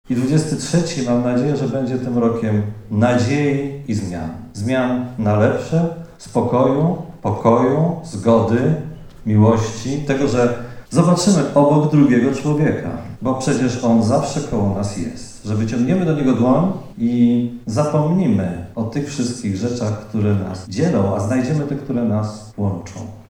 Wydarzenie, które miało miejsce w Tarnobrzeskim Domu Kultury zostało zorganizowane po dwóch latach przerwy spowodowanej pandemią koronawirusa.
Życzenia zebranym złożył prezydent Tarnobrzega, Dariusz Bożek.